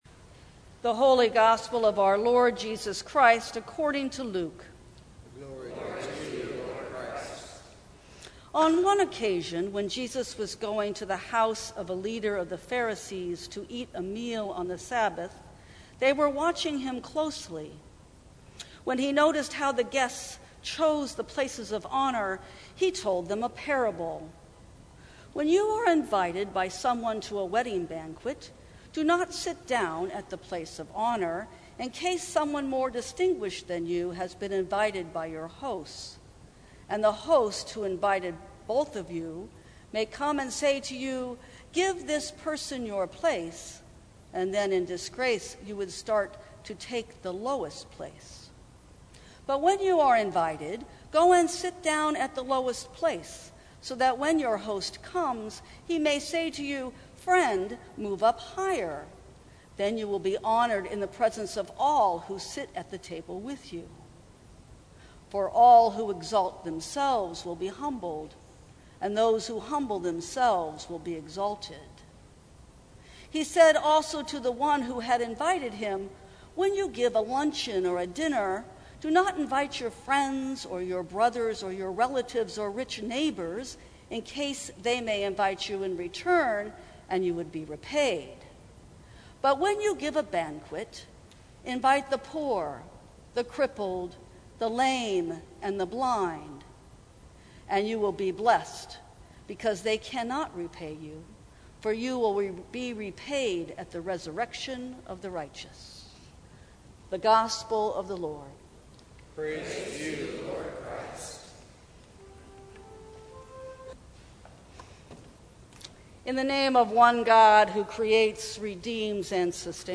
Sermons from St. Cross Episcopal Church Seating Chart Sep 04 2019 | 00:12:51 1x 00:00 / 00:12:51 Subscribe Share Apple Podcasts Spotify Overcast RSS Feed Share Link Embed